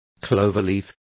Complete with pronunciation of the translation of cloverleaf.